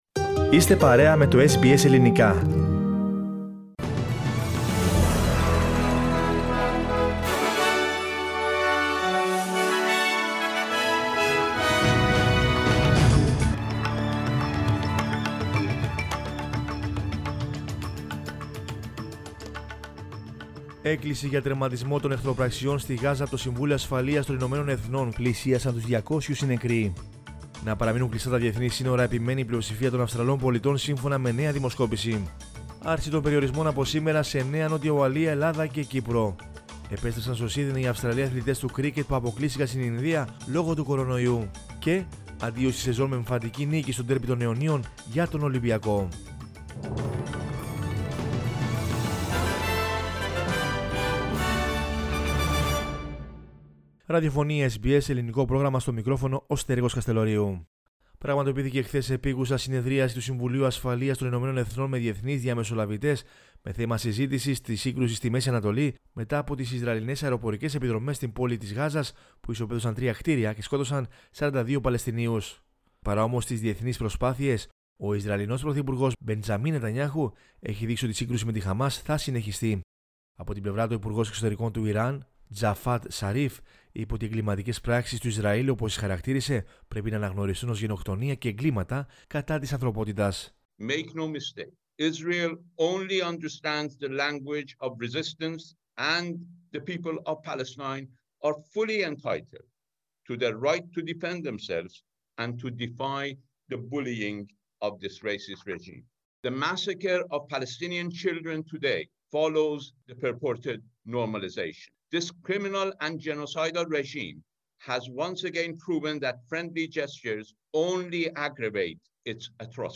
News in Greek from Australia, Greece, Cyprus and the world is the news bulletin of Monday 17 May 2021.